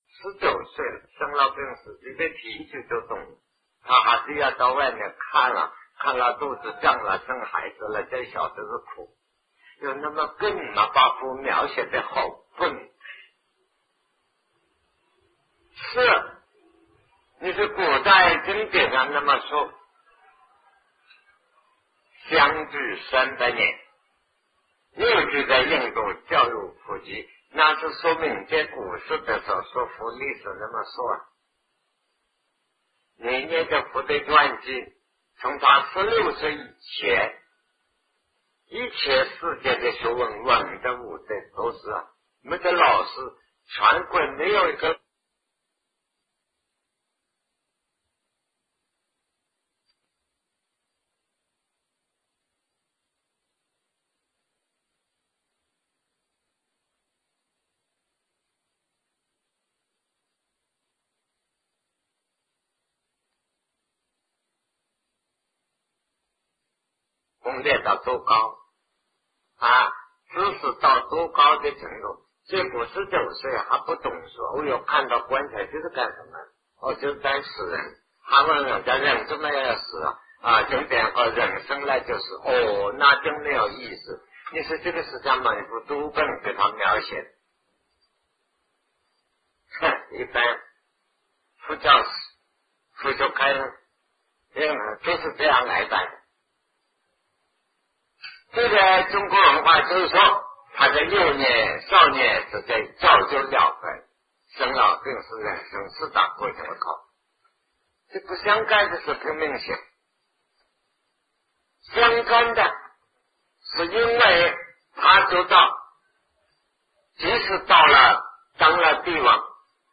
为何要讲唯识 南怀瑾先生讲唯识与中观1980代初于台湾001(下)